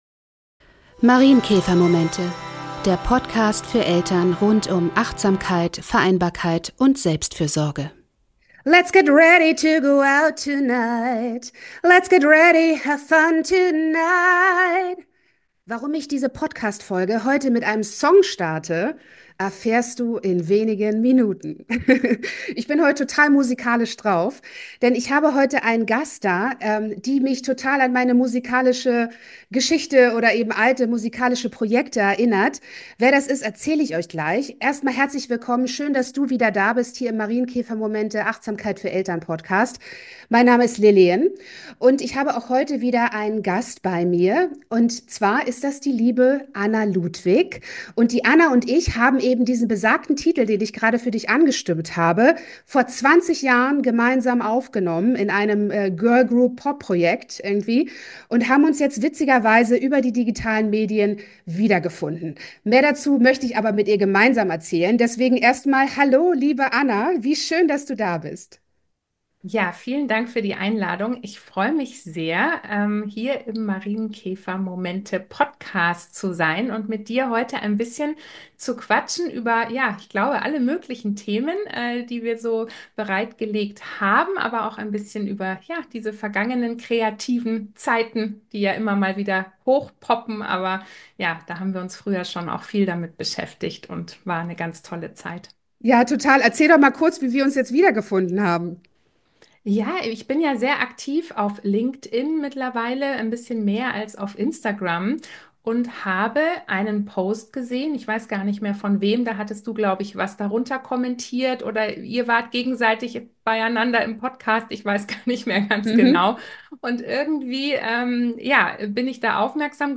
Interview-Folge